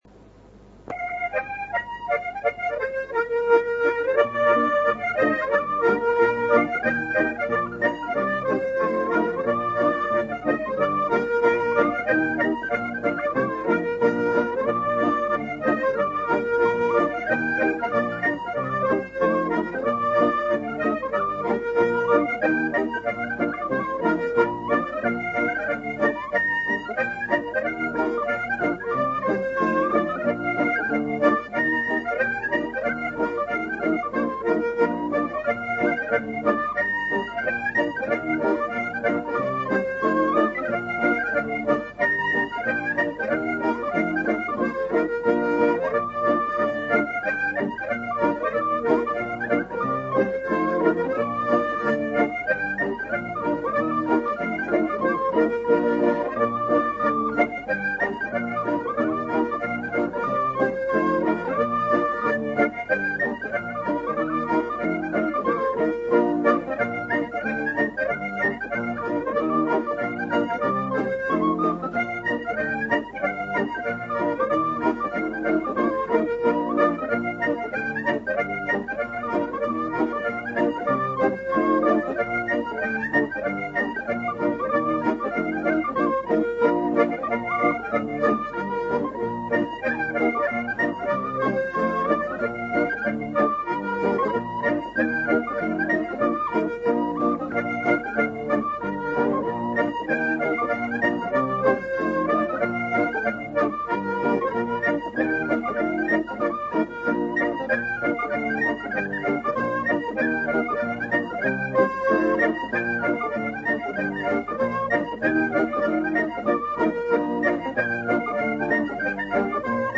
Scots Moothie
whistle
guitar